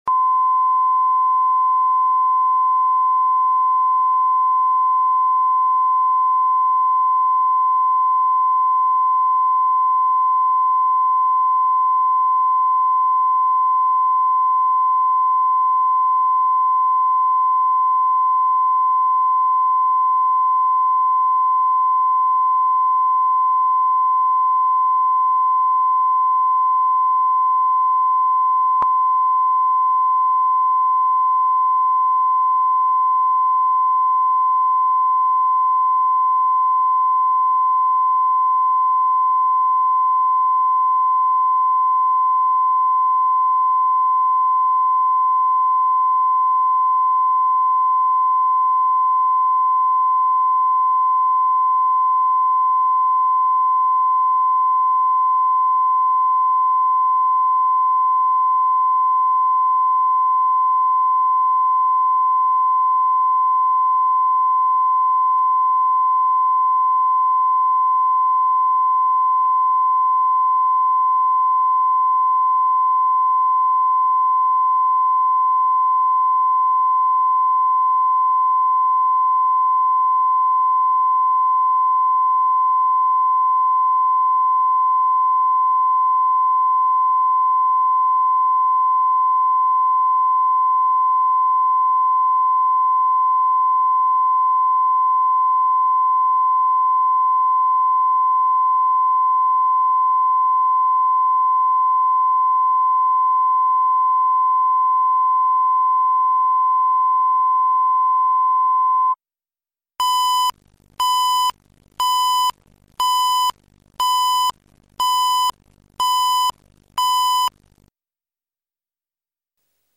Аудиокнига Невеста для мужа | Библиотека аудиокниг